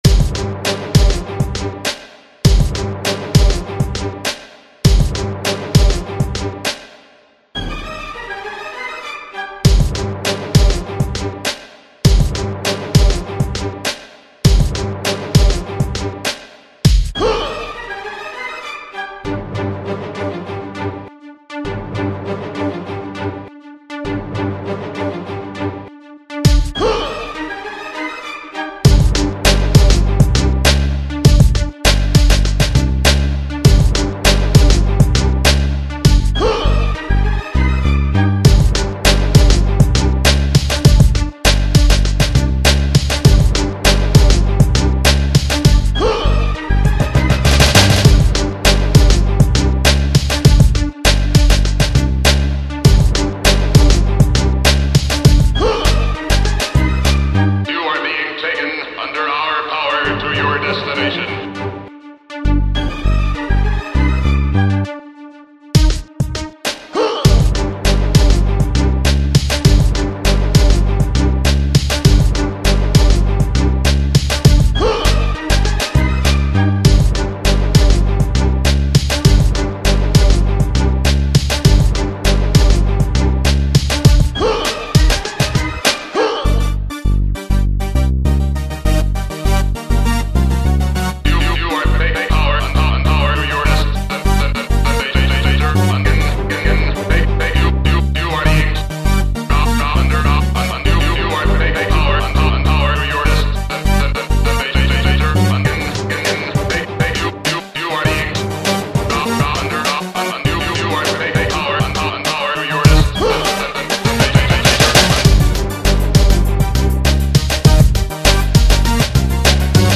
Зловещий трек! big beat